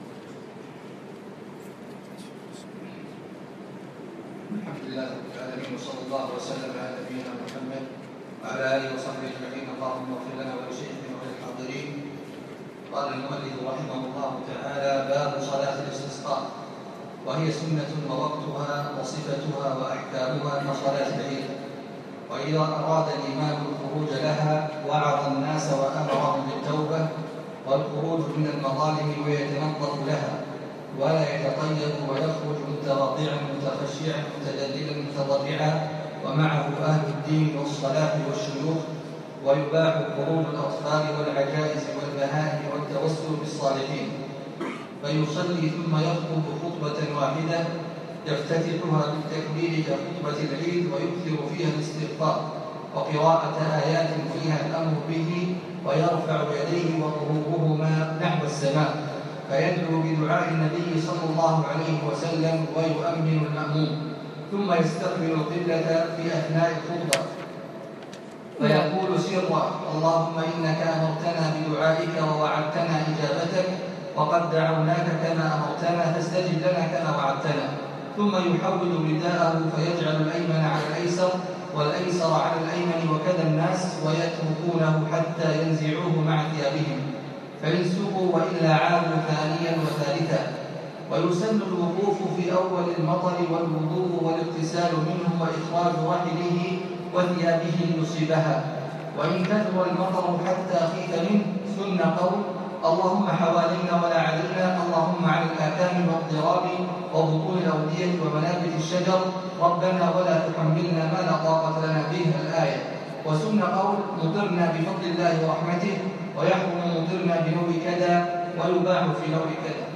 السبت 21 ذو القعدة 1436 الموافق 5 9 2015 مسجد سالم العلي الفحيحيل
الدرس السابع